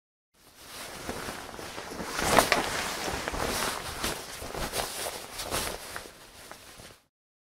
Звук шороху куртки